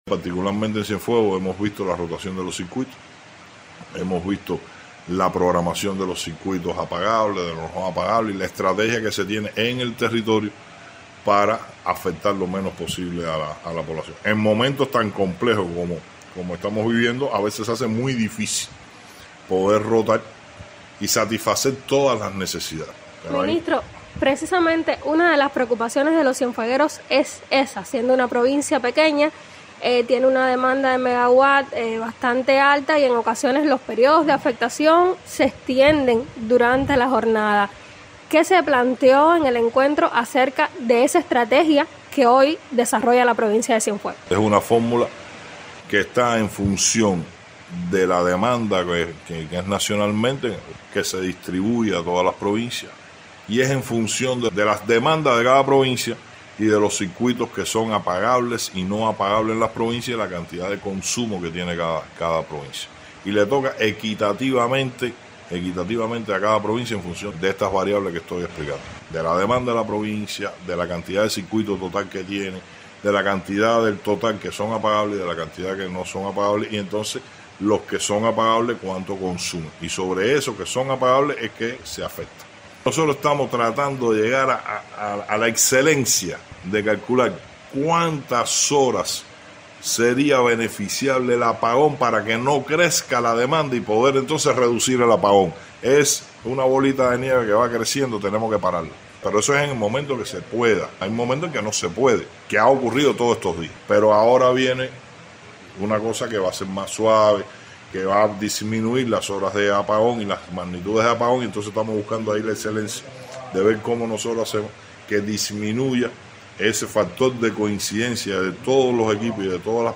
A propósito del tema escuche declaraciones ofrecidas por Vicente de la O Levy, Ministro de Energía Minas, a los Servicios Informativos de Radio Ciudad del Mar.